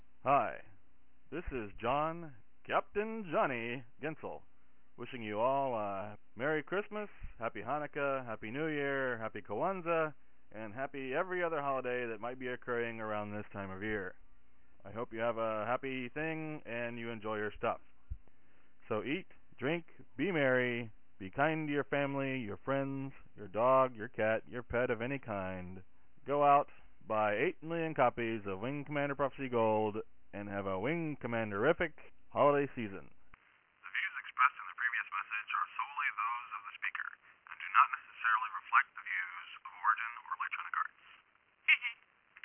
Plus, the idea of a developer wishing fans a 'wingcommanderific holiday' was pretty ahead of its time back then.